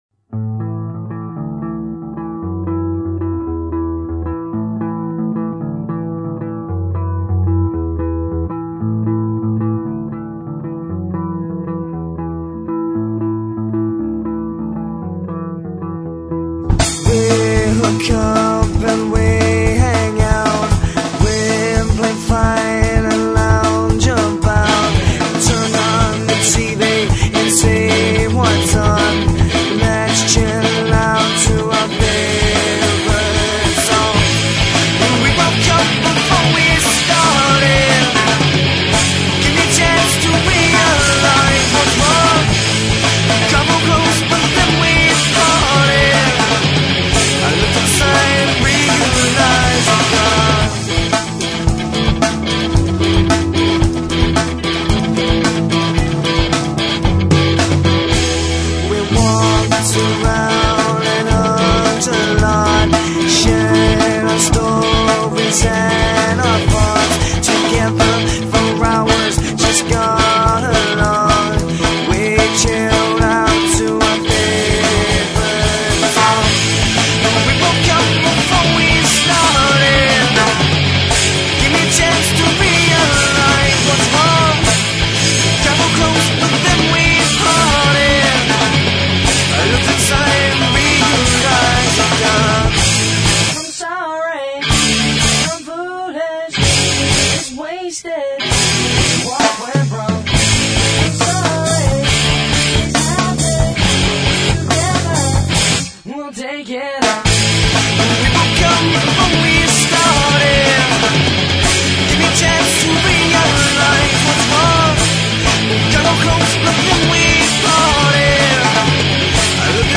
All songs written and performed by